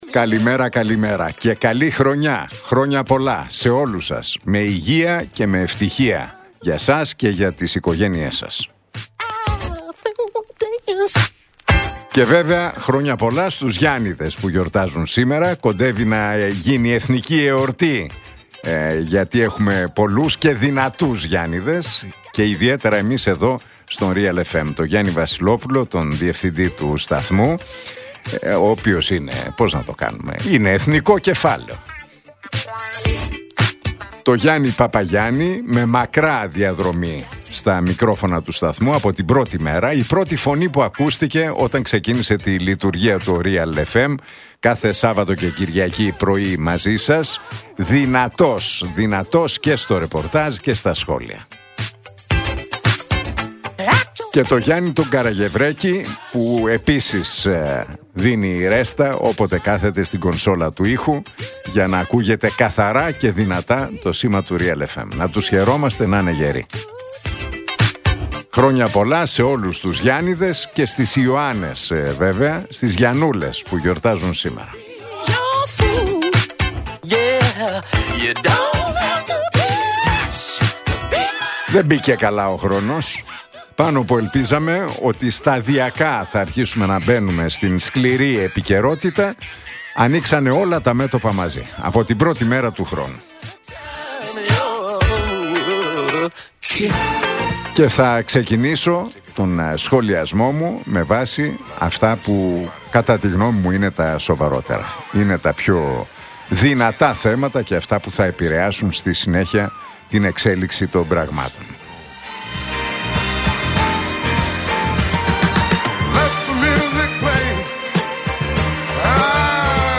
Ακούστε το σχόλιο του Νίκου Χατζηνικολάου στον ραδιοφωνικό σταθμό Realfm 97,8, την Τετάρτη 7 Ιανουαρίου 2026.